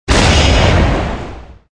impact_cruise_disruptor.wav